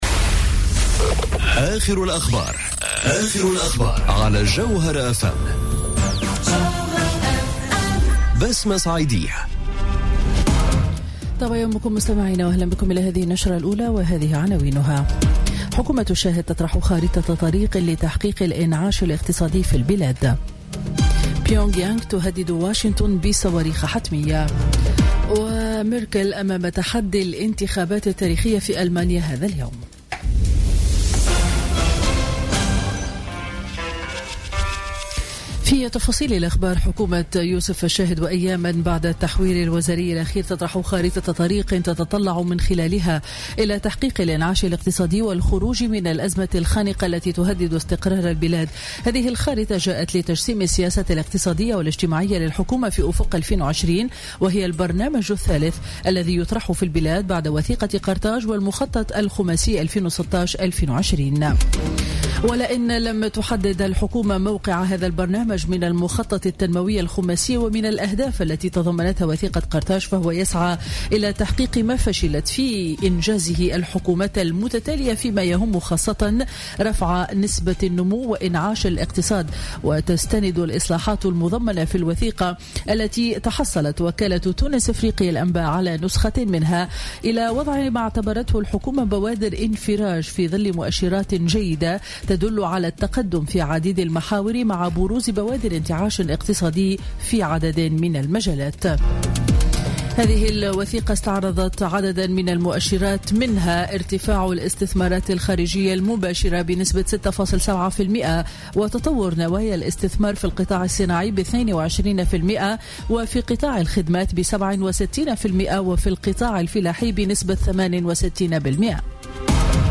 نشرة أخبار السابعة صباحا ليوم الأحد 24 سبتمبر 2017